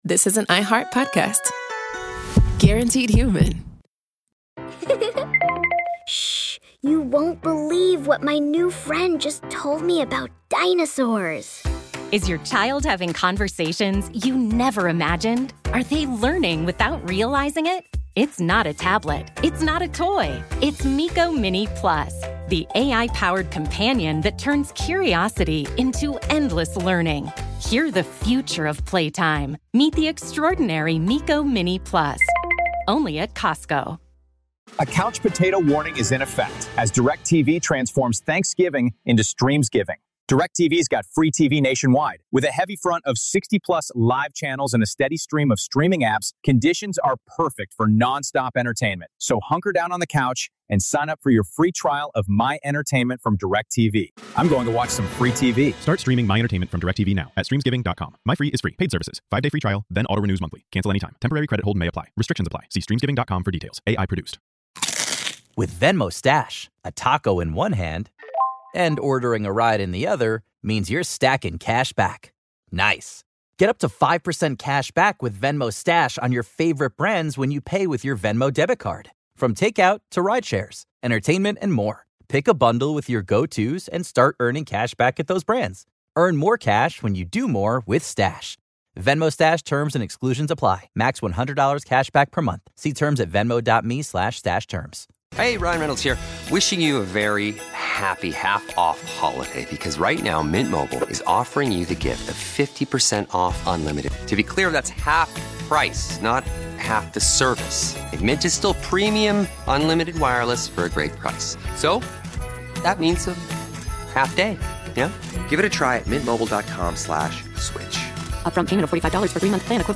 Each call traces the shape of faith, regret, and forgiveness inside a place built for punishment.